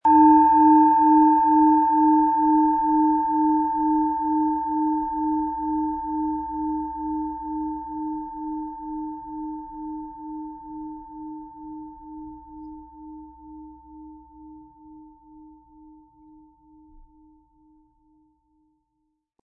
Planetenton
Es ist eine von Hand gearbeitete tibetanische Planetenschale Eros.
Um den Original-Klang genau dieser Schale zu hören, lassen Sie bitte den hinterlegten Sound abspielen.
Im Lieferumfang enthalten ist ein Schlegel, der die Schale harmonisch zum Klingen und Schwingen bringt.
MaterialBronze